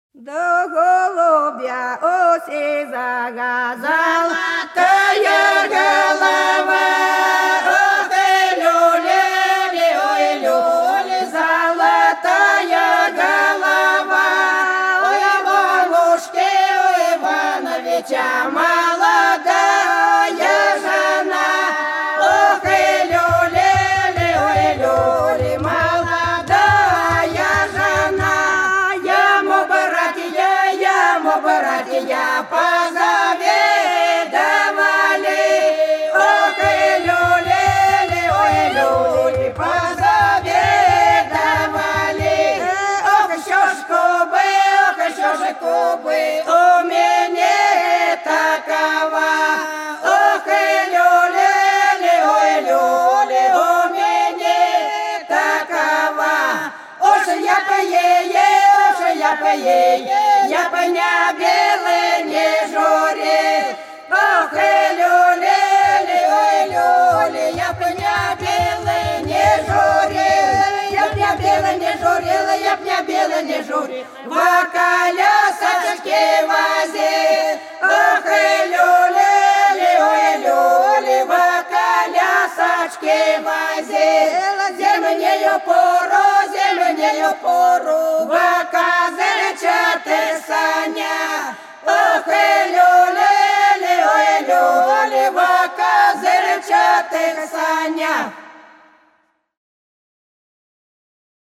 Хороша наша деревня У голубя, у сизого золотая голова - свадебная (с. Иловка)
07_У_голубя,_у_сизого_золотая_голова_-_свадебная.mp3